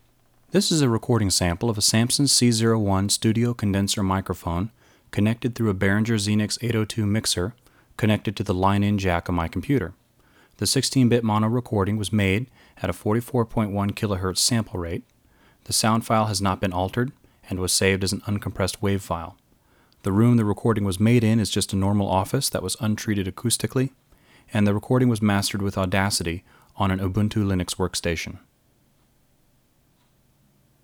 For anyone who might be considering this gear combo for home recording/podcasting (or any random audio heads out there), here’s an uncompressed sample recording I just made in my office of the Samson C01 condensor microphone connected to the line in through a Behringer Xenyx 802 mixer.